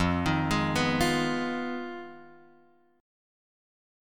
Fm7b5 chord {1 2 1 1 0 x} chord